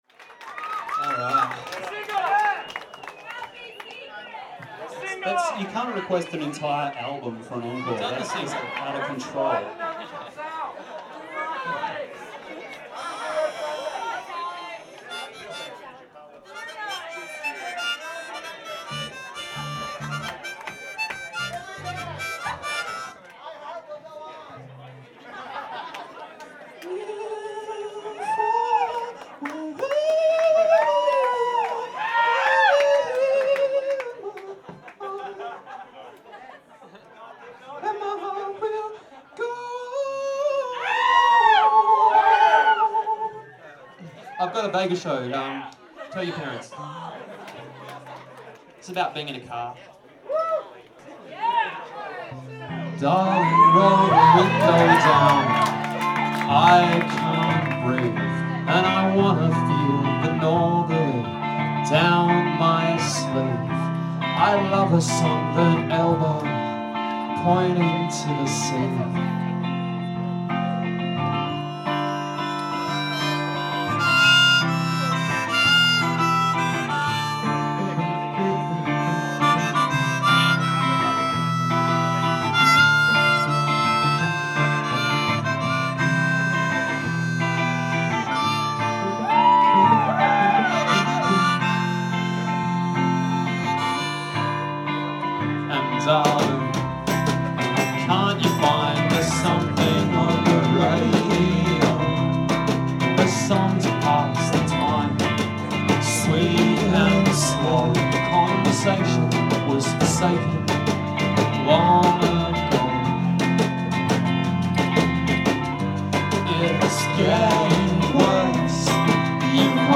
live at PA’s Lounge
Somerville, Massachusetts